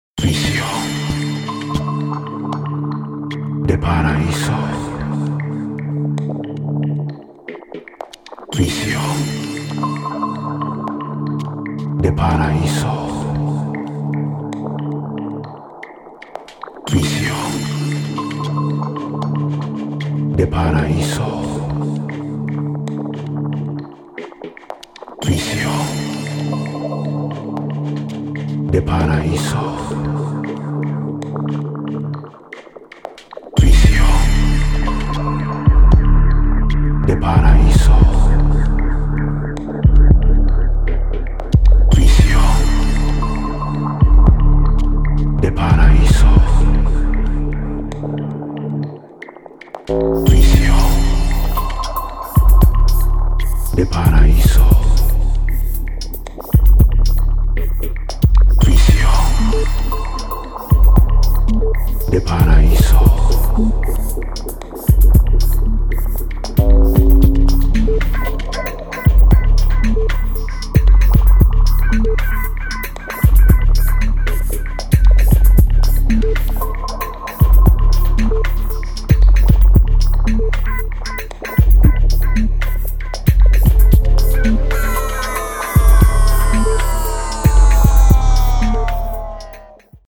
Electro Techno